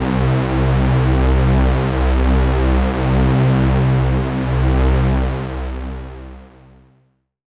Index of /90_sSampleCDs/Best Service ProSamples vol.36 - Chillout [AIFF, EXS24, HALion, WAV] 1CD/PS-36 AIFF Chillout/AIFF Synth Atmos 2